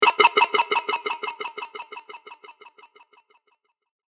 Laser Delays 16
Antidote_Zodiac-Laser-Delays-16.mp3